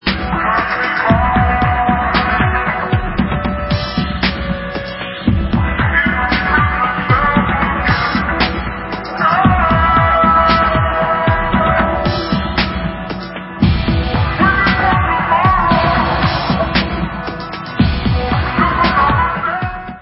hrající fantastický R&B pop.